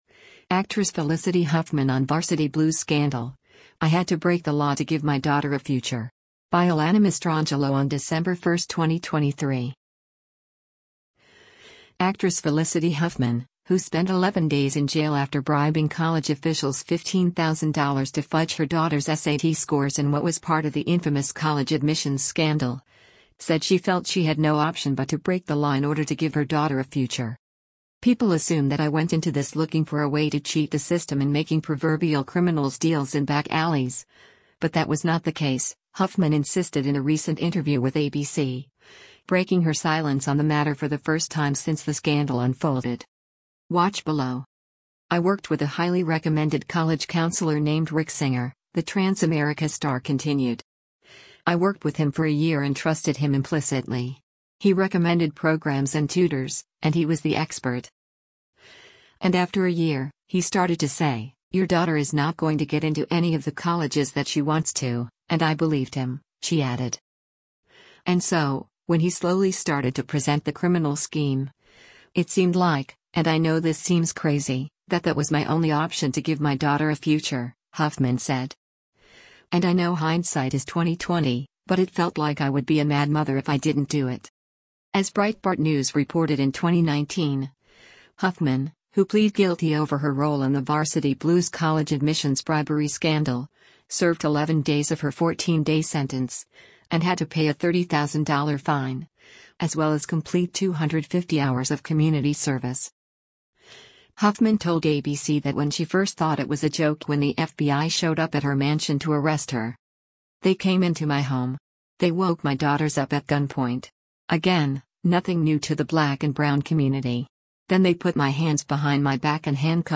“People assume that I went into this looking for a way to cheat the system in making proverbial criminals deals in back alleys, but that was not the case,” Huffman insisted in a recent interview with ABC, breaking her silence on the matter for the first time since the scandal unfolded.